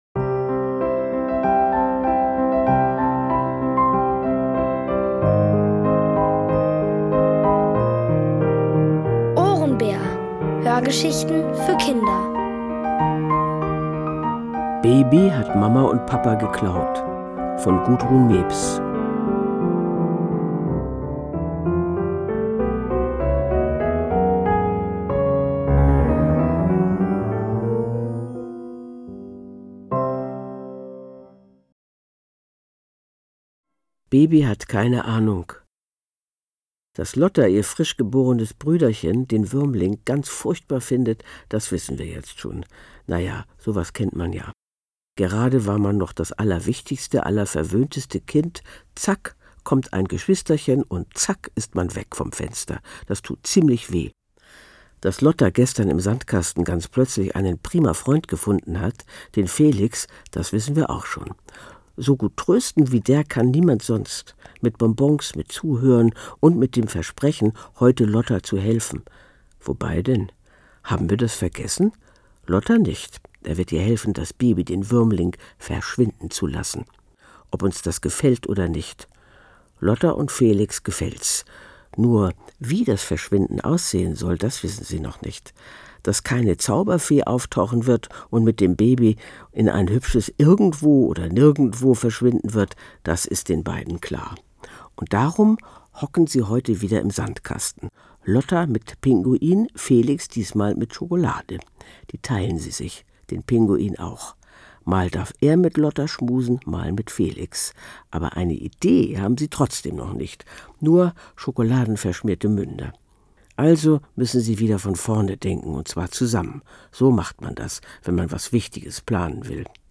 Von Autoren extra für die Reihe geschrieben und von bekannten Schauspielern gelesen.
Es liest: Hannelore Hoger.